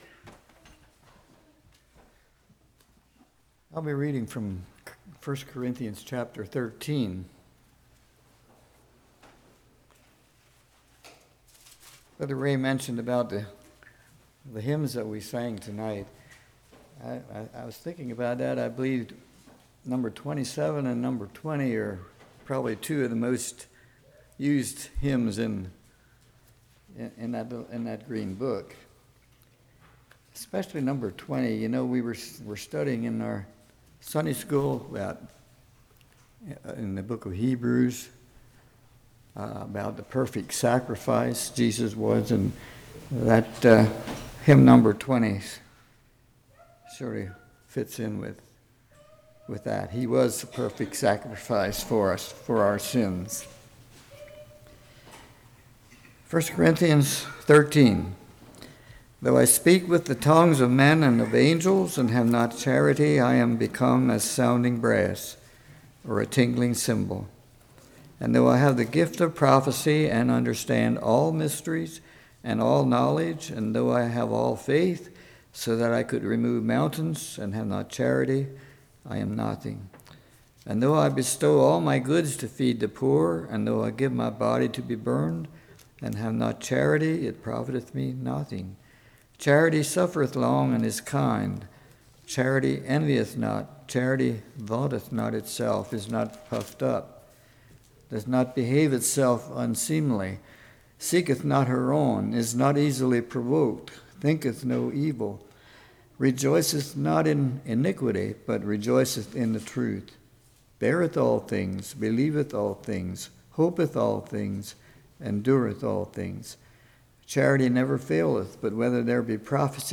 1 Corinthians 13:1-13 Service Type: Evening v1-3 what love is not v4-8 Love described We can take love along to Heaven « Why